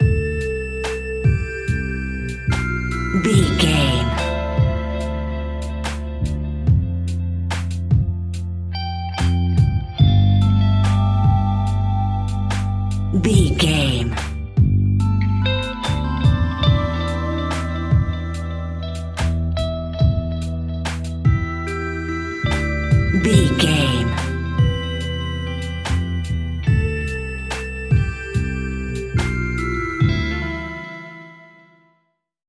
Ionian/Major
E♭
chilled
laid back
Lounge
sparse
new age
chilled electronica
ambient
atmospheric